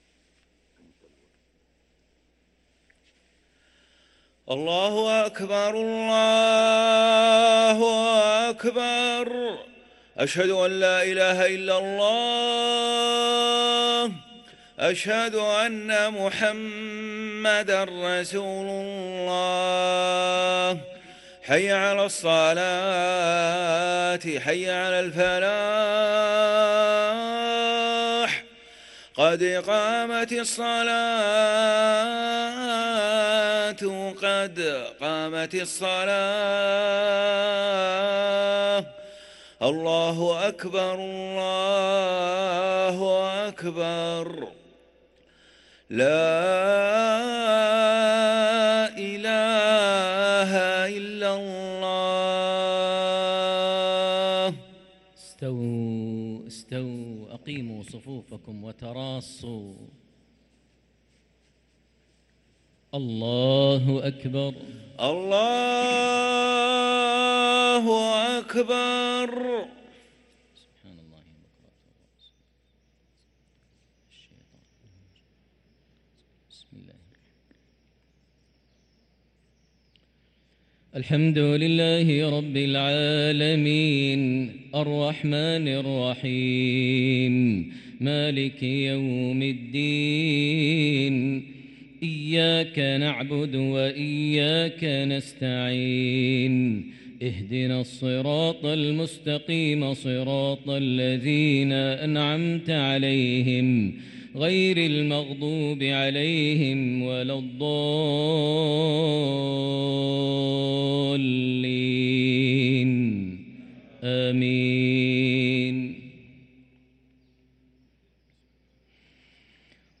صلاة العشاء للقارئ ماهر المعيقلي 12 جمادي الآخر 1445 هـ
تِلَاوَات الْحَرَمَيْن .